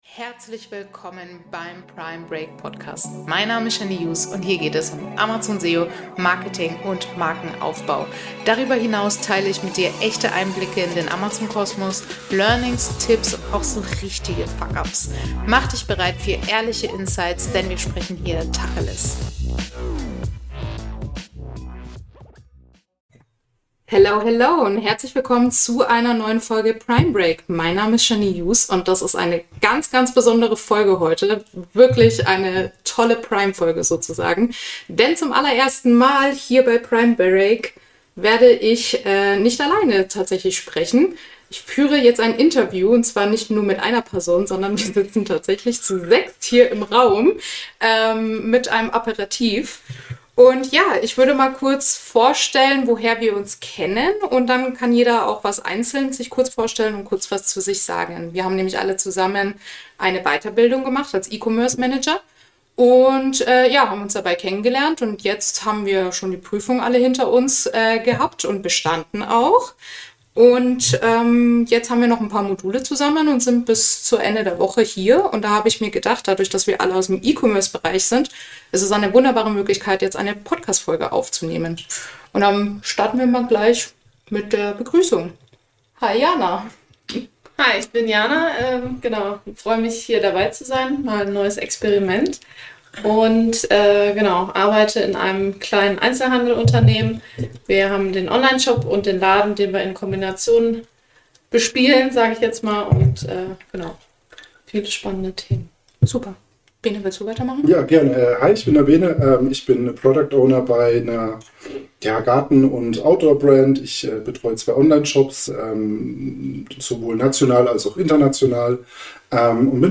Genau: Es wird ehrlich, laut, emotional – und vor allem witzig Wir sind sechs Menschen, die den Sprung in den E-Commerce gewagt haben – ohne klassischen Hintergrund, dafür mit umso mehr Erfahrung aus der Praxis.
Sondern ein ehrlicher Deep Talk über echtes Unternehmertum im digitalen Handel. Tonqualität? Naja, sagen wir mal „Live-Atmosphäre pur“ – aber der Inhalt macht’s wett.